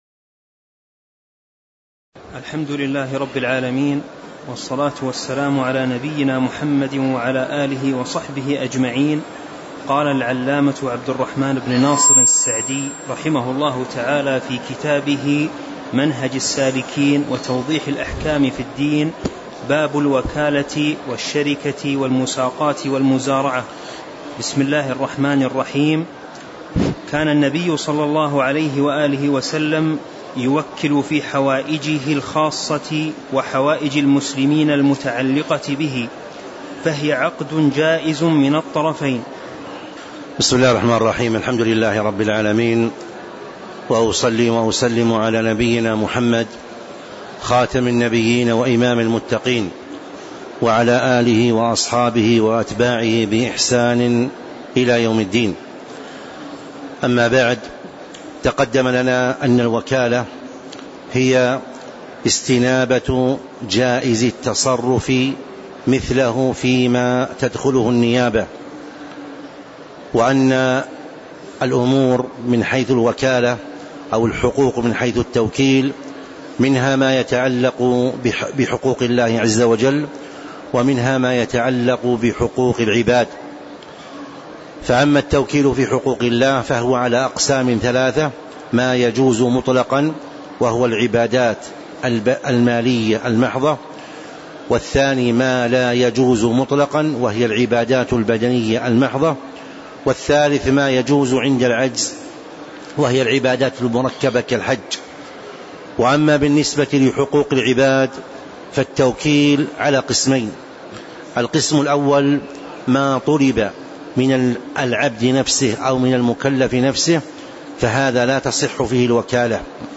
تاريخ النشر ٢٣ محرم ١٤٤٦ هـ المكان: المسجد النبوي الشيخ